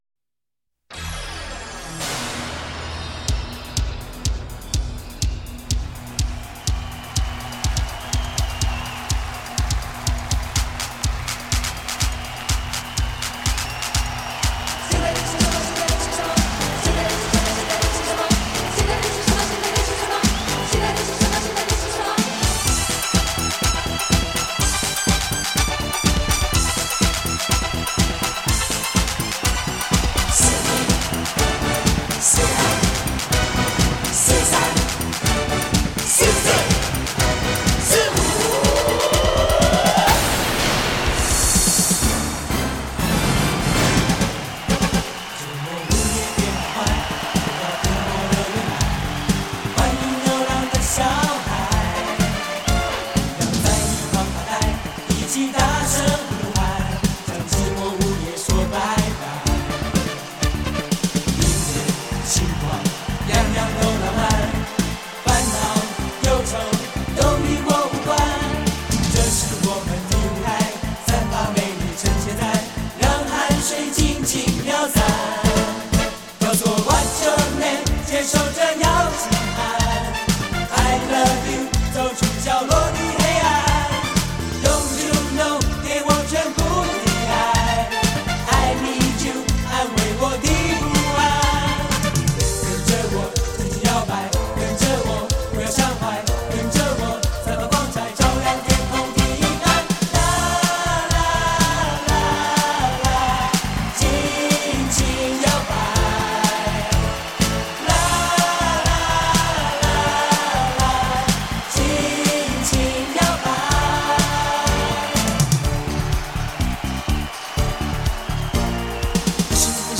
港台流行金曲大联唱
45就是 采45转快转的方式演唱串联当红歌曲的组曲 记录着70.80年代台湾流行乐史